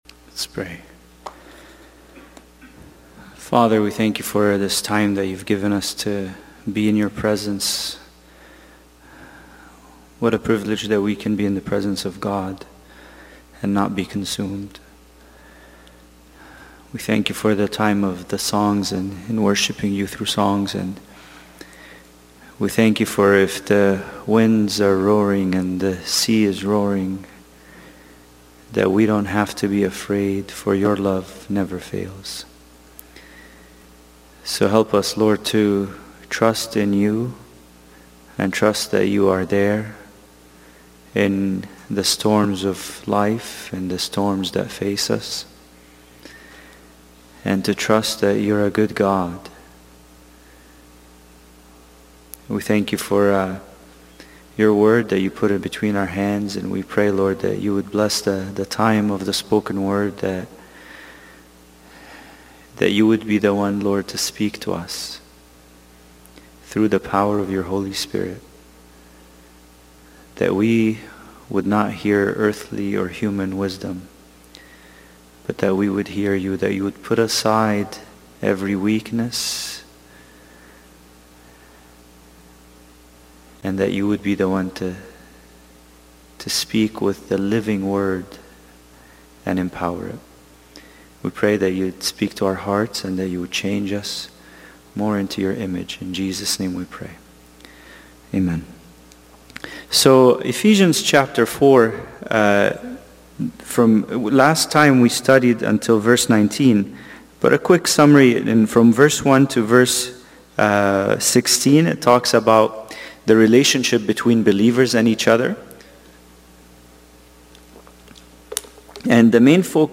Bible Study: Ephesians 4:20-23